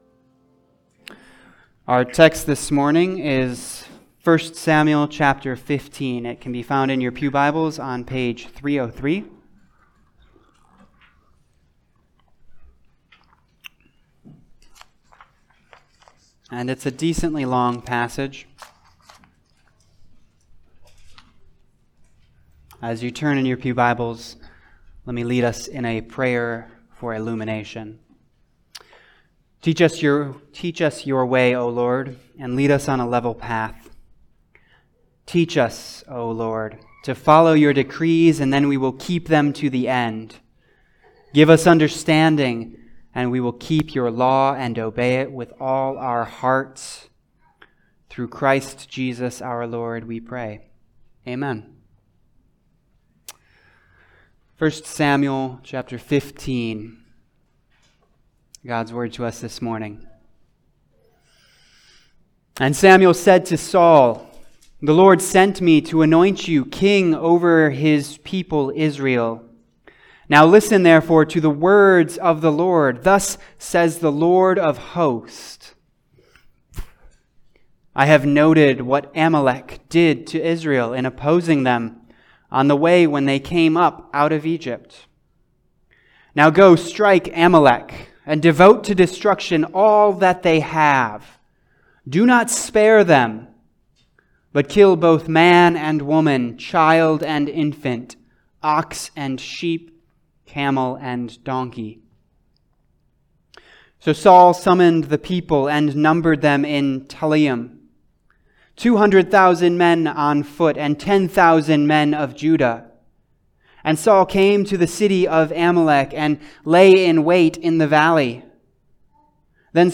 Passage: 1 Samuel 15 Service Type: Sunday Service